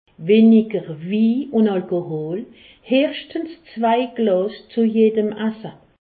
Haut Rhin
Ville Prononciation 68
Pfastatt